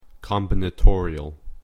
/ˌkɑm.bɪ.nəˈtɔɹ.i.əɫ(米国英語)/